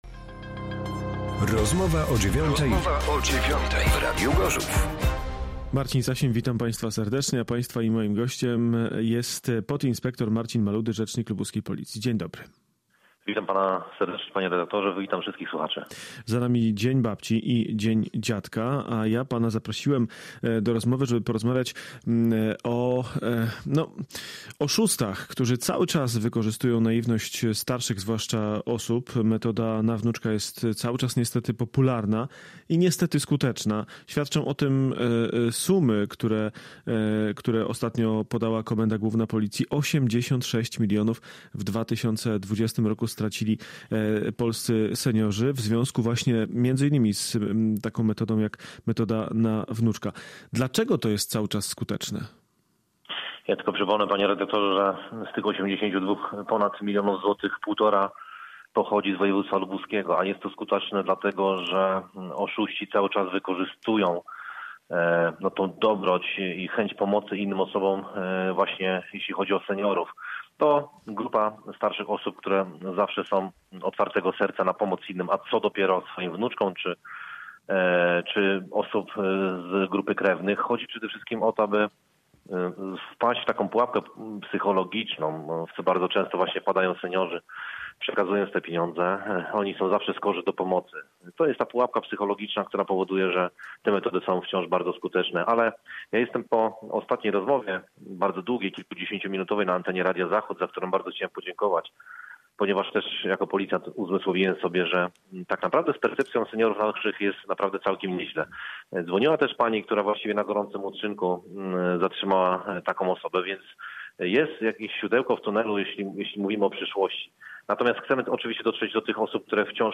Gość na 95,6FM